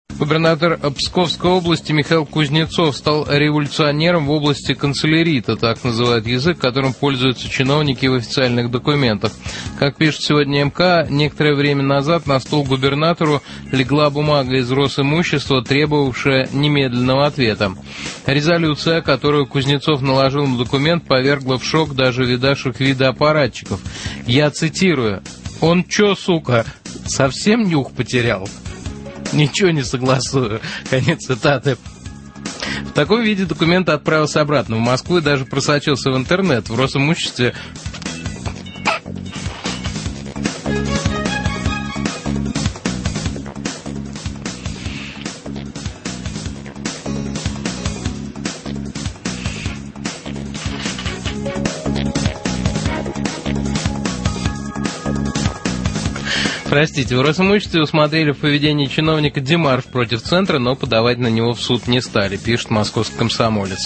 Прямой эфир на Эхо Москвы :) Резолюция :))